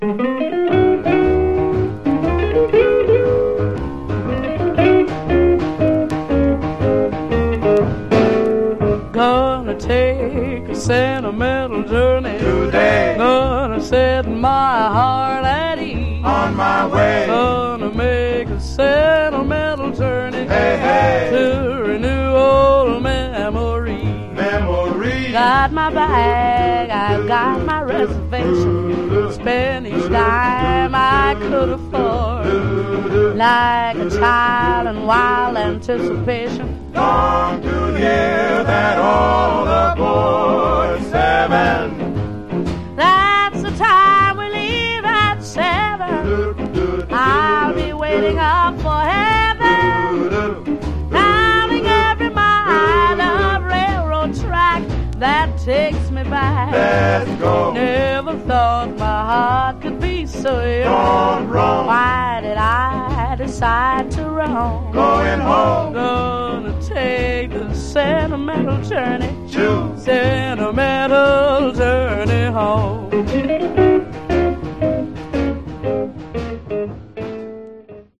Genre: Rockin' RnB